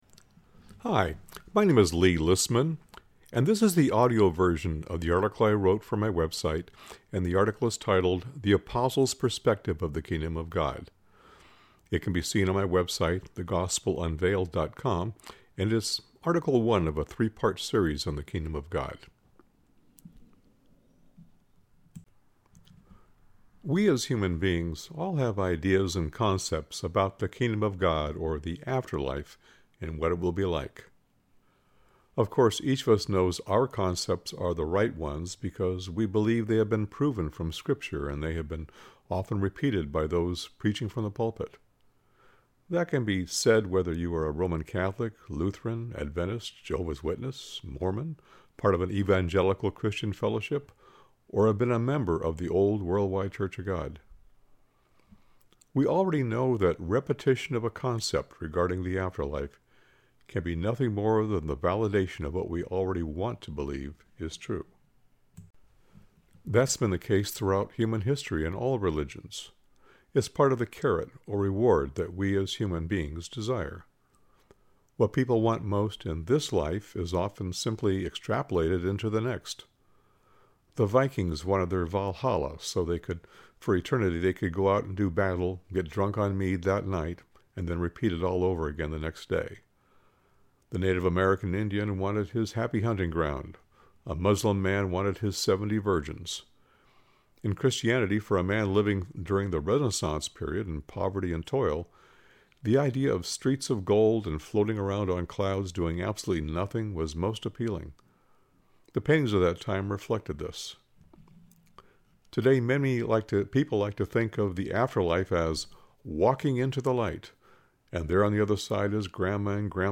(Click Here if you wish to listen to the article read by myself)